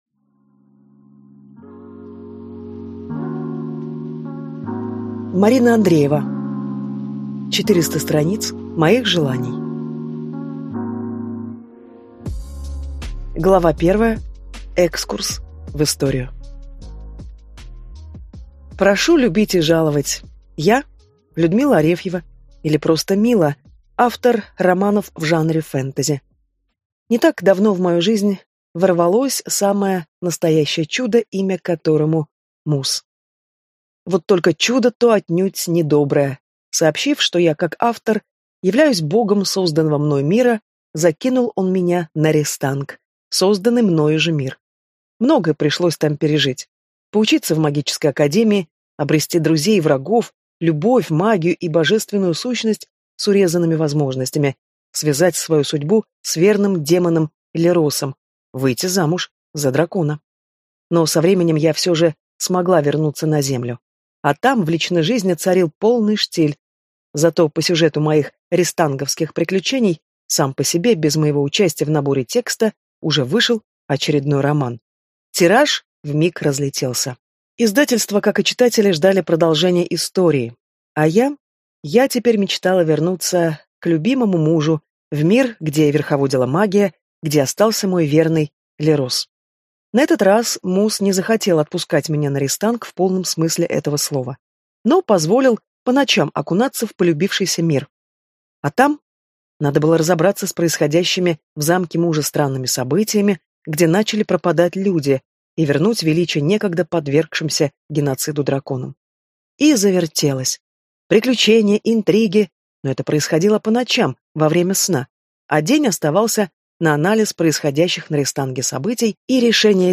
Аудиокнига 400 страниц моих желаний | Библиотека аудиокниг